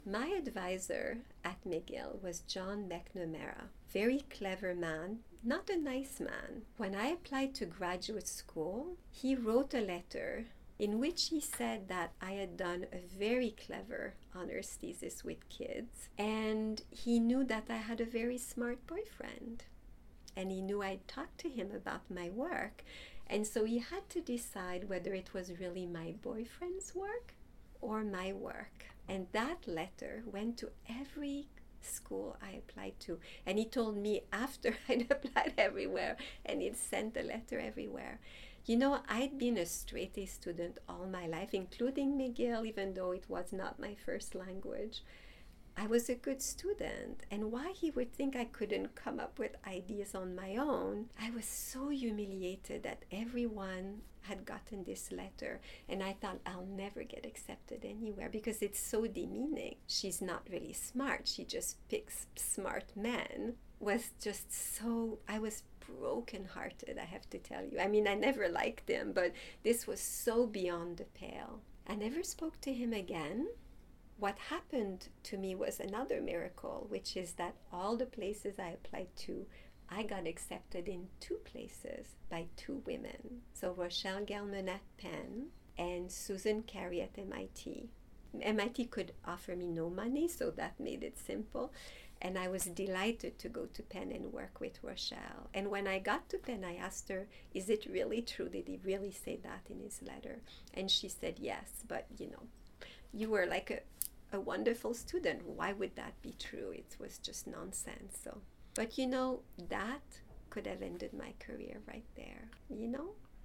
Here, Dr. Baillargeon describes her process applying to graduate studies and the letter of “support” provided by her undergraduate mentor.